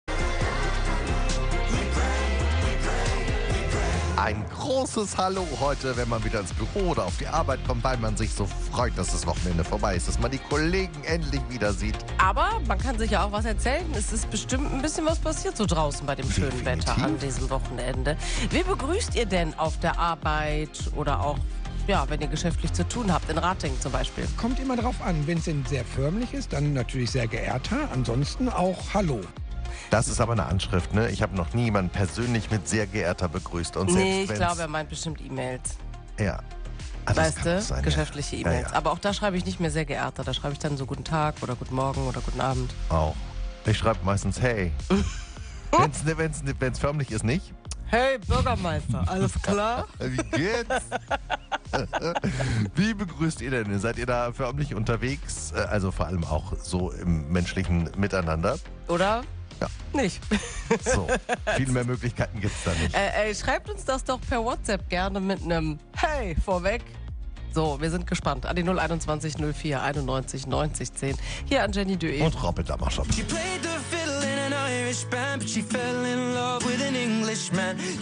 Wir haben uns bei euch umgehört und spannende Antworten gesammelt!